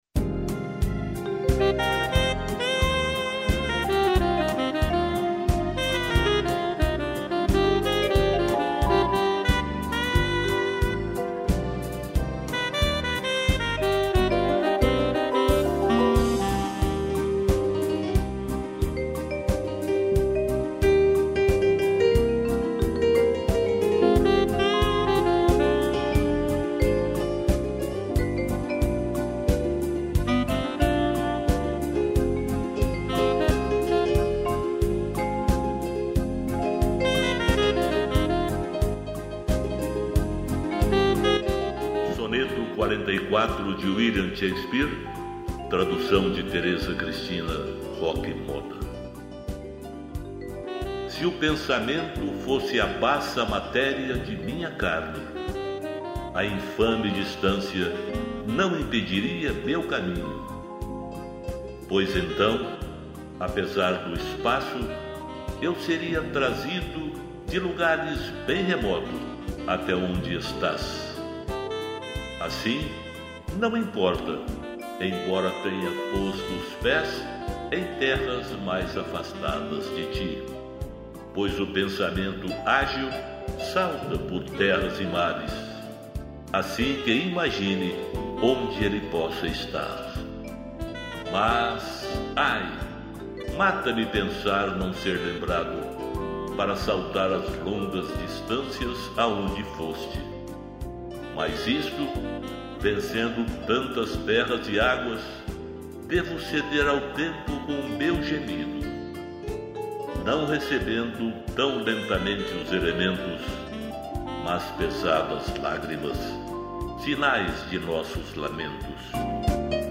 piano, vibrafone e sax